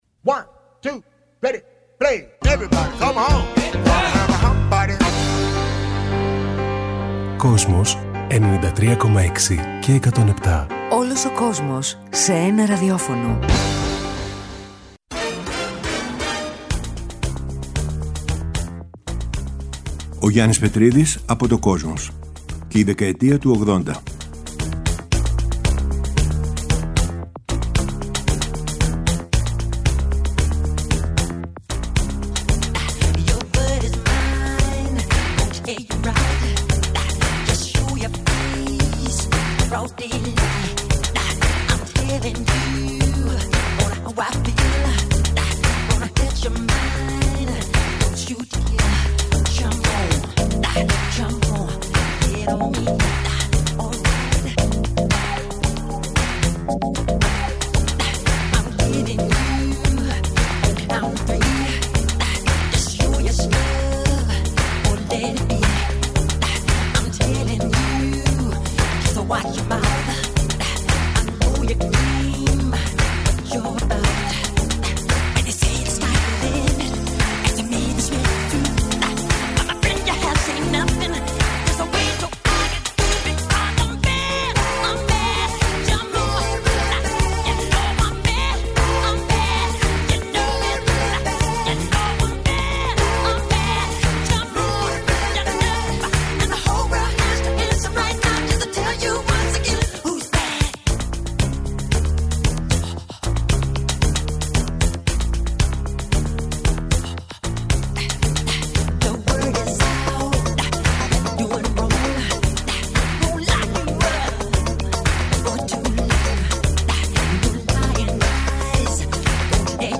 Μετά την ολοκλήρωση του αφιερώματος στη μουσική του 21ου αιώνα, ο Γιάννης Πετρίδης παρουσιάζει ένα νέο μουσικό αφιέρωμα στην 20ετία 1980-2000, από την Κυριακή 14 Μαρτίου 2021 και κάθε Κυριακή στις 19:00 στο Kosmos 93.6. Παρουσιάζονται, το ξεκίνημα της rap, η μεταμόρφωση του punk σε new wave, οι νεορομαντικοί μουσικοί στην Αγγλία, καθώς και οι γυναίκες της pop στην Αμερική που άλλαξαν τη δισκογραφία.